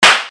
PLAY Woman Slap
woman-slap.mp3